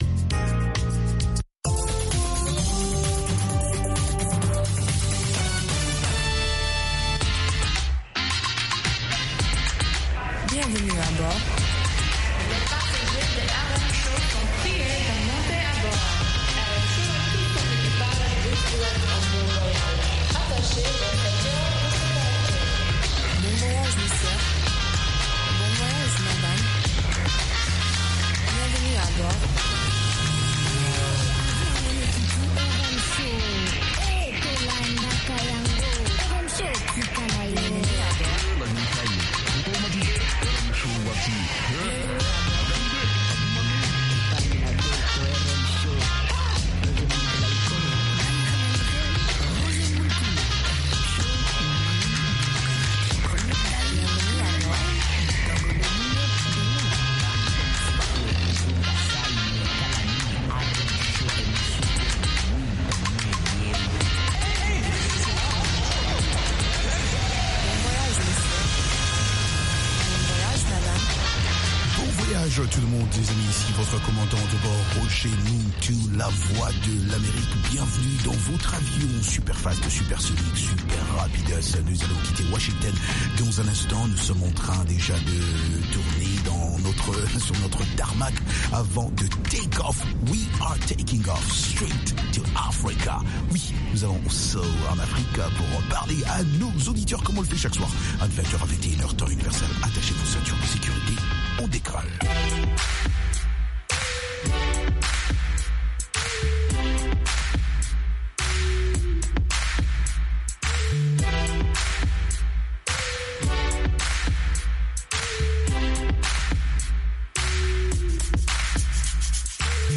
RM Show -Musique internationale & comedie